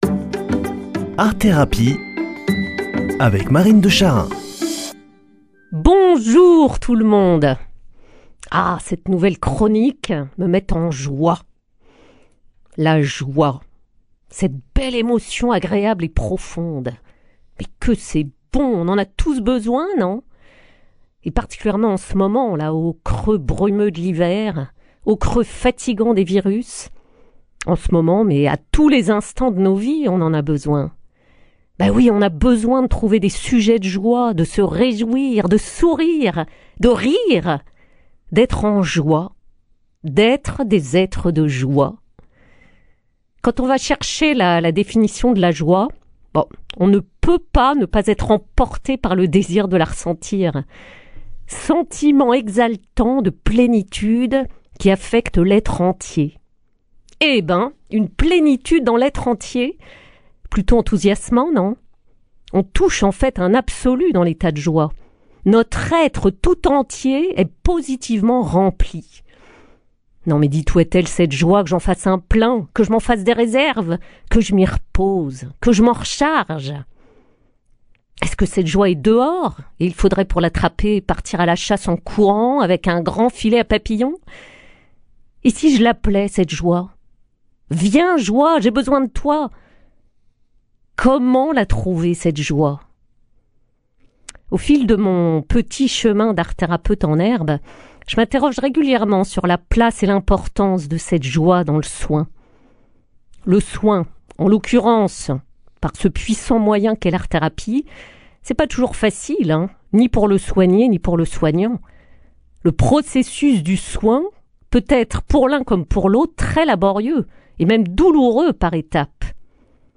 [ Rediffusion ] L’art-thérapie comme chemin vers la joie ?
Présentatrice